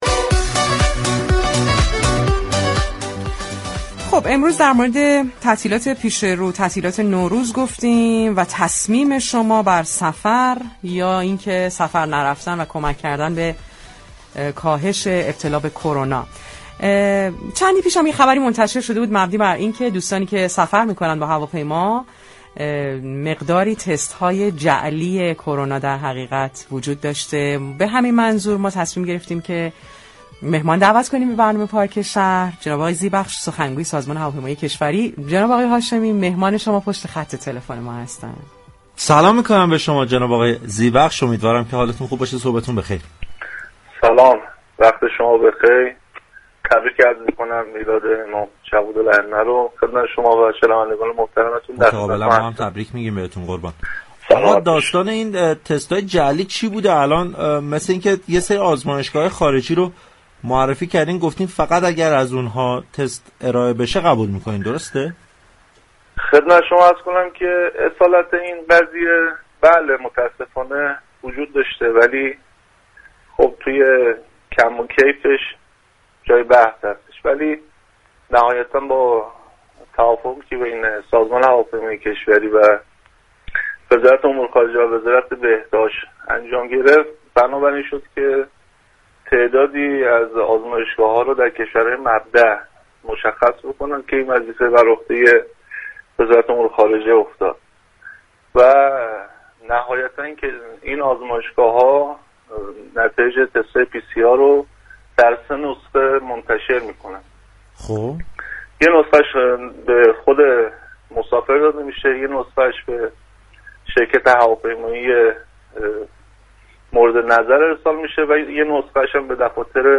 در گفتگو با برنامه پارك شهر